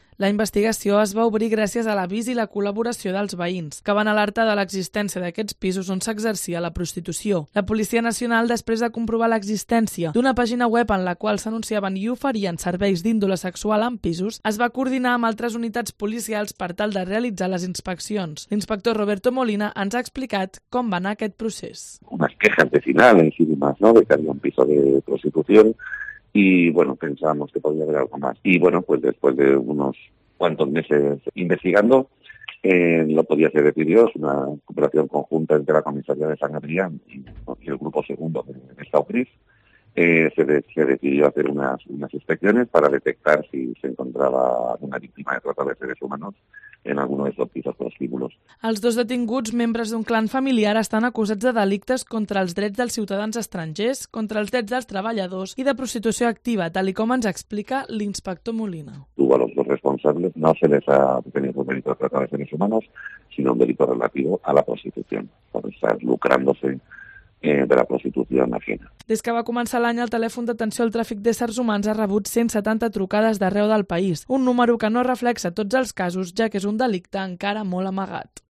Desmantelan dos prostíbulos en Santa Coloma de Gramanet - Crónica